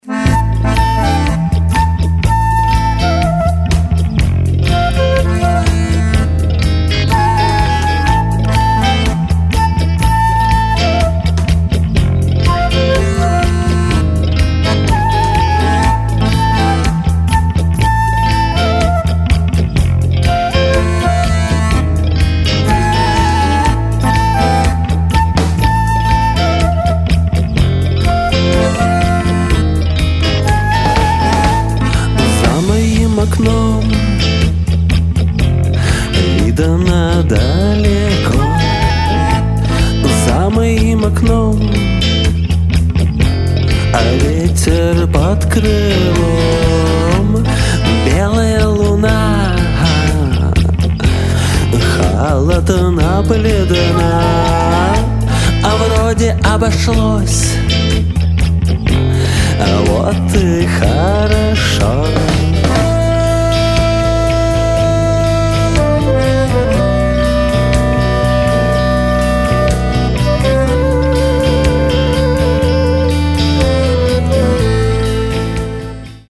нежный дуэт баяна и флейты
зафуззованными гитарой и голосом
Если добавить к этому нервные басовые партии
и своеобразную переменчивую ритмику барабанов